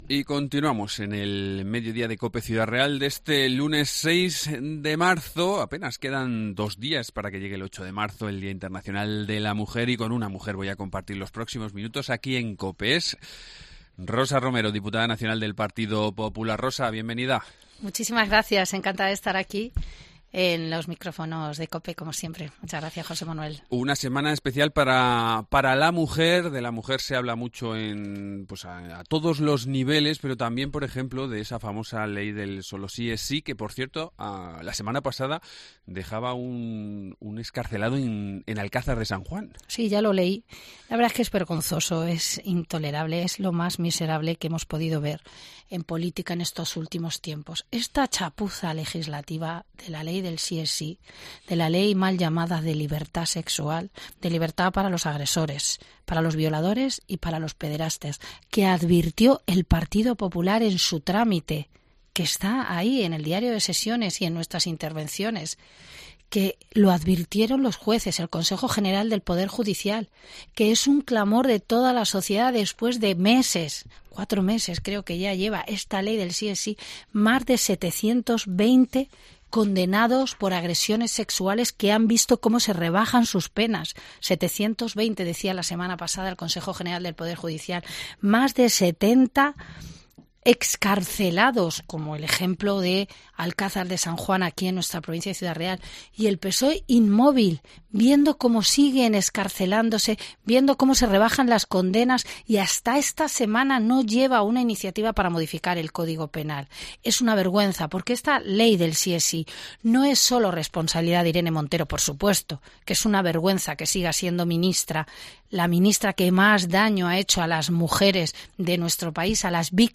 Entrevista
Entrevista con Rosa Romero, diputada nacional del PP